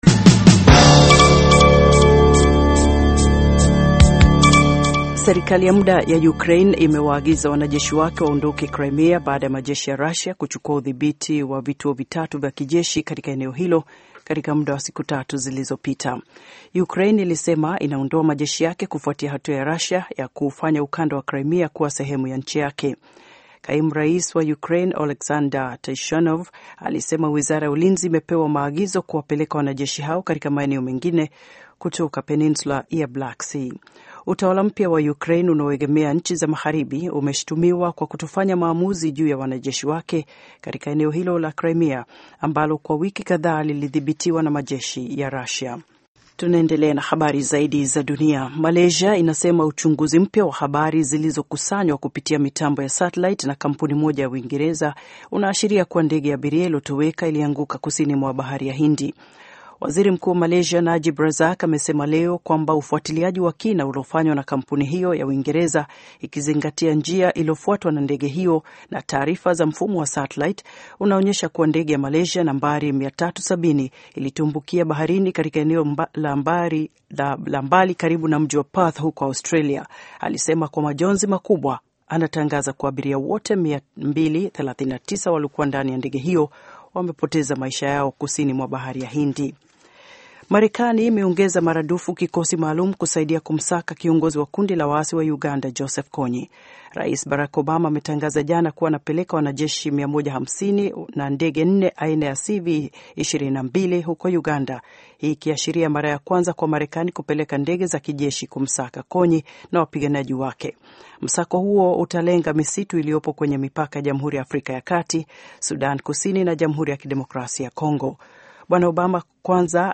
Taarifa ya Habari VOA Swahili - 4:02